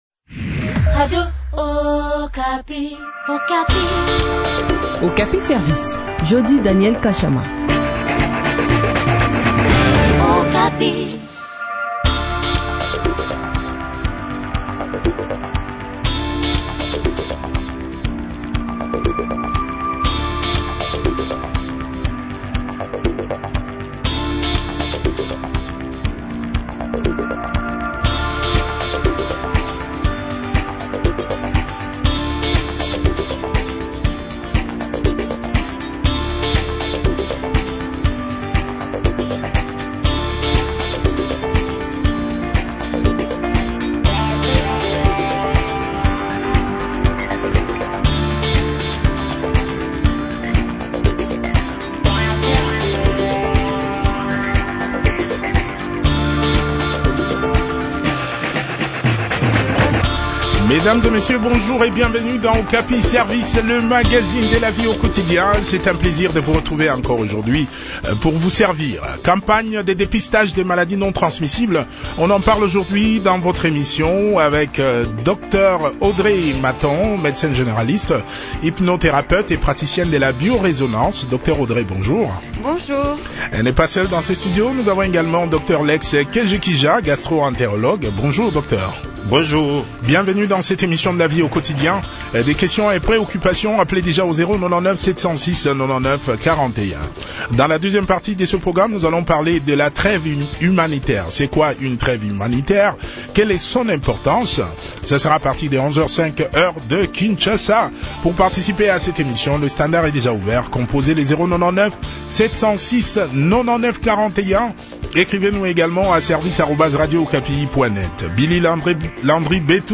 hypnothérapeute et praticienne de la bio-résonnance a également pris part à cette interview.